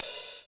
20 OP HAT.wav